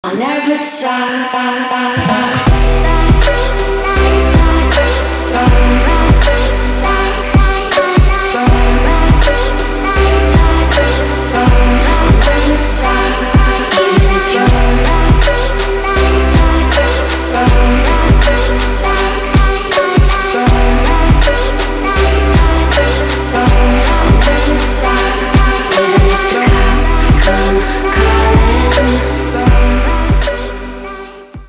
• Качество: 320, Stereo
женский вокал
Electronic
EDM
Chill Trap
Melodic Trap
Красивый отрывок c забавным голосом, похожим на детский